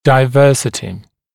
[daɪ’vɜːsɪtɪ][дай’вё:сити]разнообразие; многообразие; разнородность